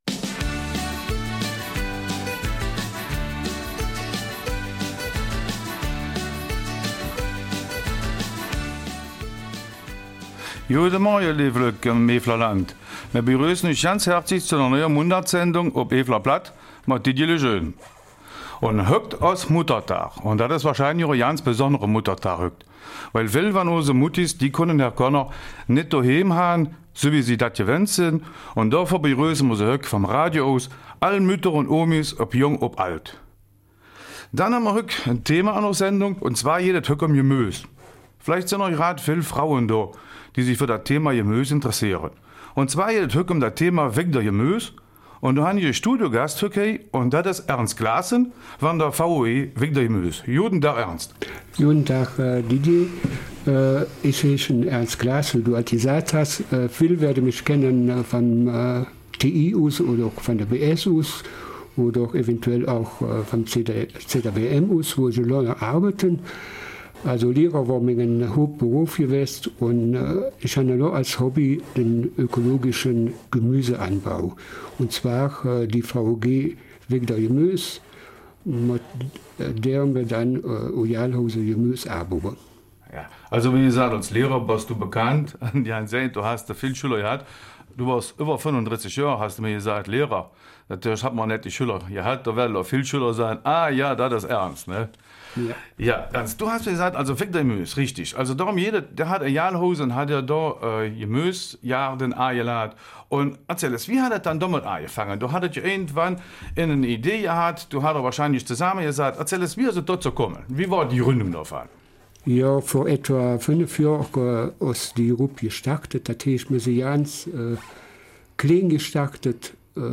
Studiogast in der Eifeler Mundartsendung vom 10.